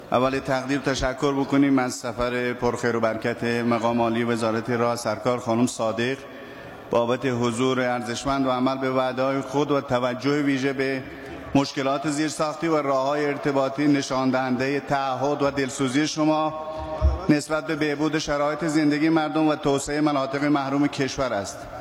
نماینده سراوان، سیب و مهرستان ضمن تشکر از سفر وزیر راه و شهرسازی به سیستان و بلوچستان، در صحن علنی مجلس گفت: توجه و تلاش وزیر راه و شهرسازی برای حل مشکلات زیرساختی نشان دهنده تعهد نسبت به بهبود زندگی و توسعه در مناطق محروم است.